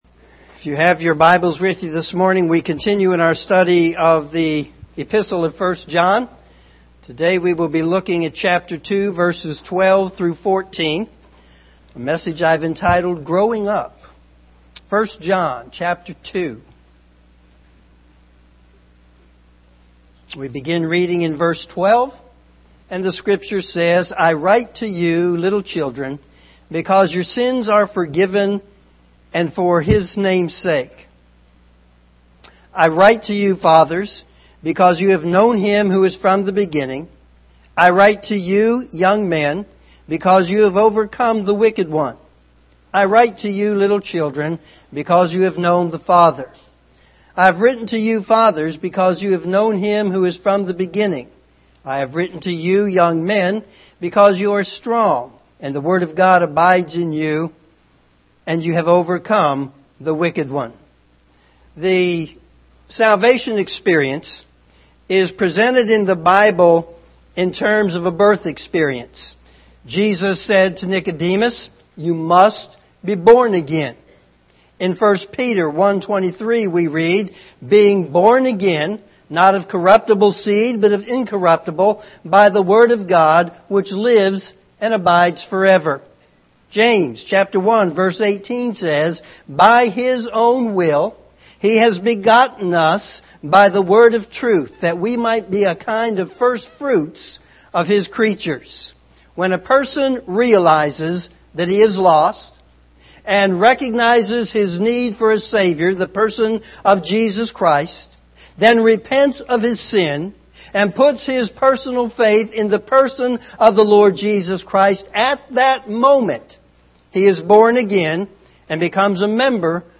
Morning Sermon
sermon2-11-18am.mp3